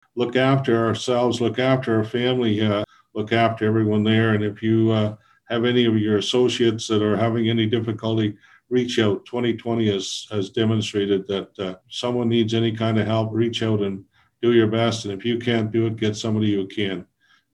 Quinte West Mayor asks residents to stick together during State of the City address
Members of the Quinte West business community got an update from the mayor on Friday, as Jim Harrison gave a state of the city address to the chamber of commerce.